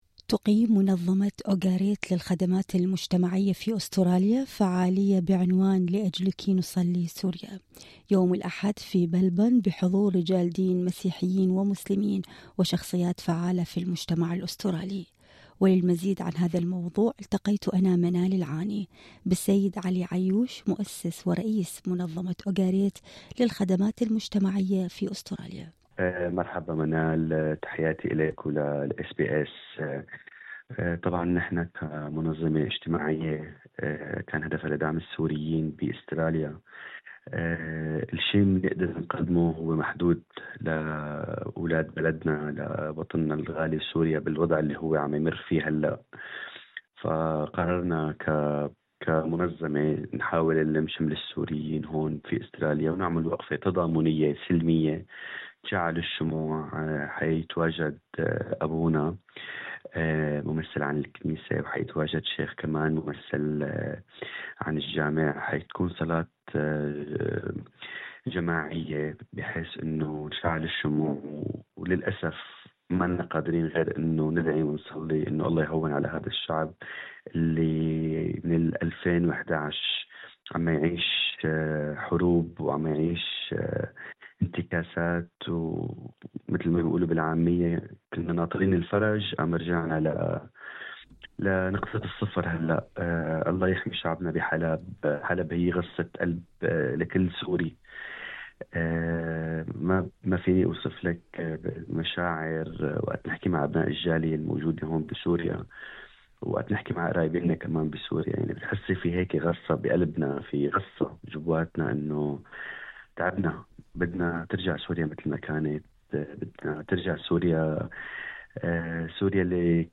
المقابلة الصوتية